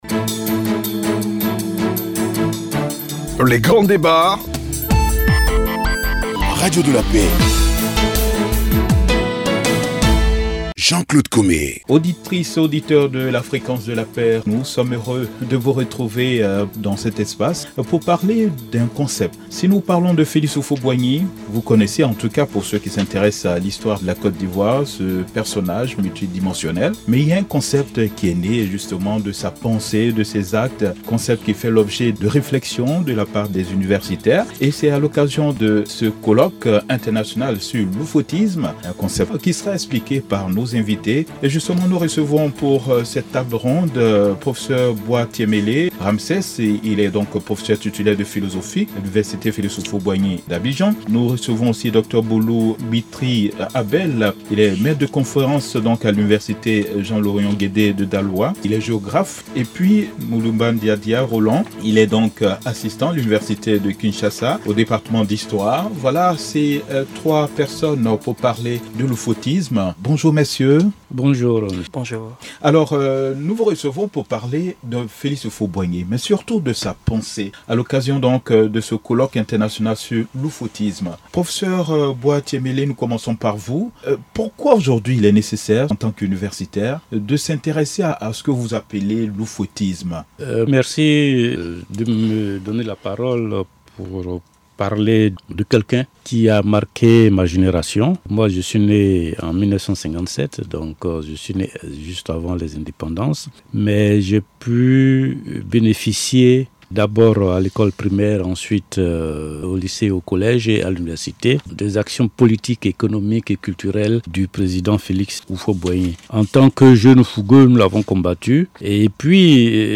Table Ronde N°1 – L’houphouëtisme
table-ronde-n1-lhouphouetisme.mp3